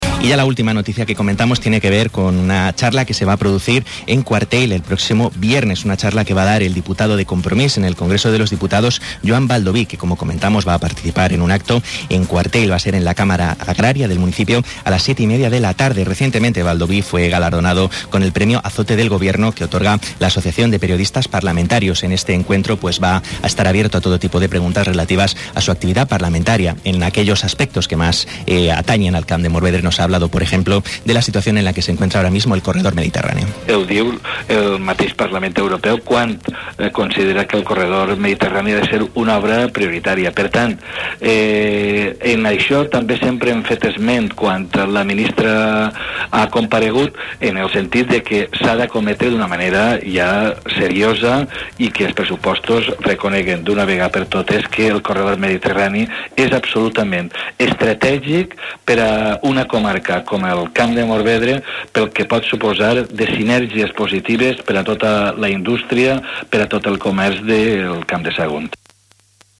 Continuant amb la campanya d’informació per a l’acte del proper 17 de gener a Quartell, hi ha sigut entrevistat en Onda Cero Sagunto Joan Baldoví, el qual ha destacat la importància del corredor mediterrani en l’economia del País Valencià, vos deixem el tall de veu.